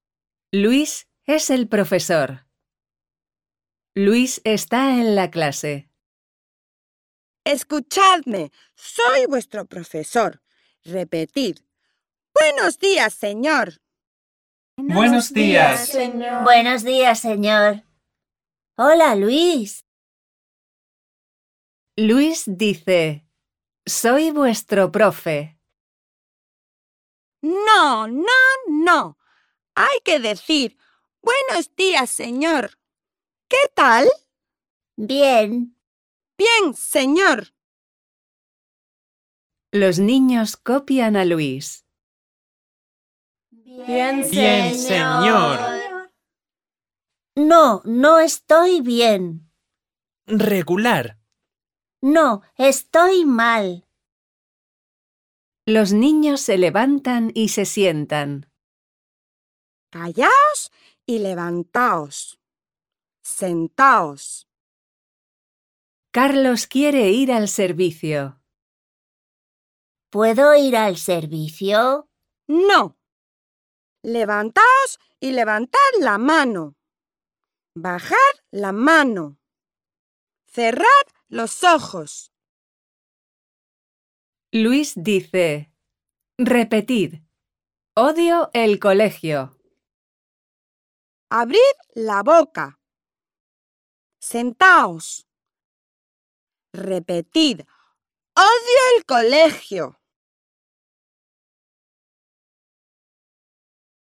To help non-specialist teachers, model good pronunciation and provide valuable practice in listening skills, the book comes with free audio downloads and English translations of the stories.